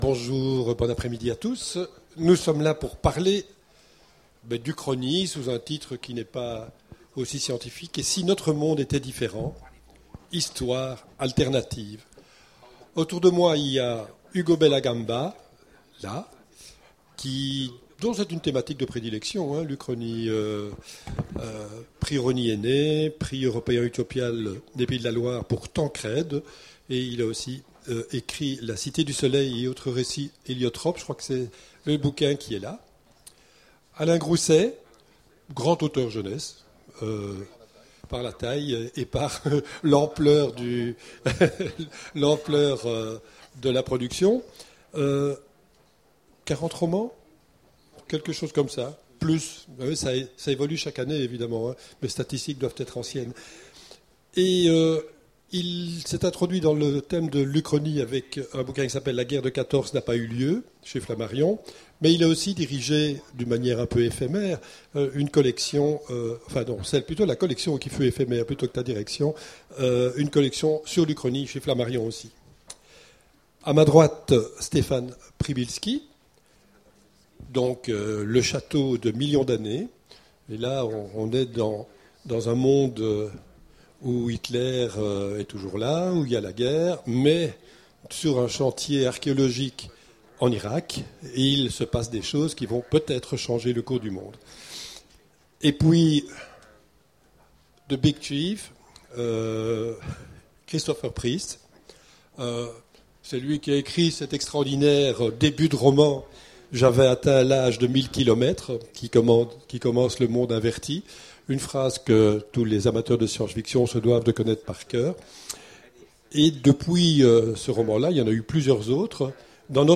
Imaginales 2015 : Conférence Et si notre monde était différent ?